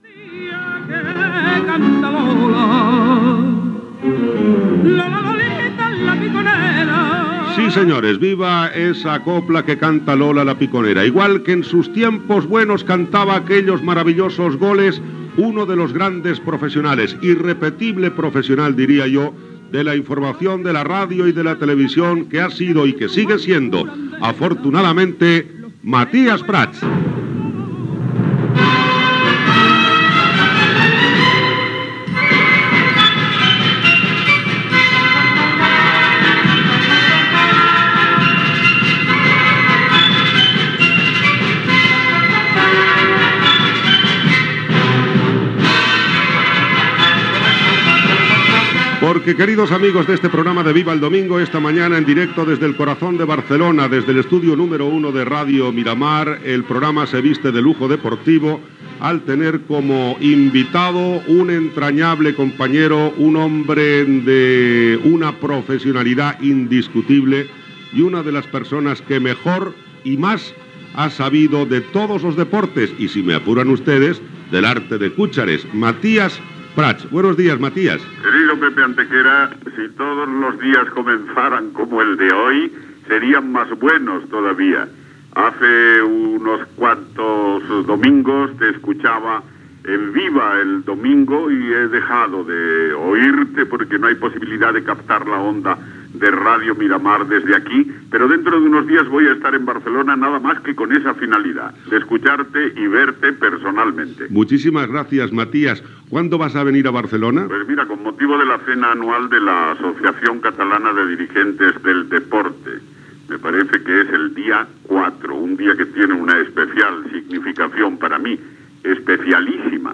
Entrevista a Matías Prats sobre la seva trajectòria esportiva i taurina, la seva relació amb Barcelona i la seva jubilació.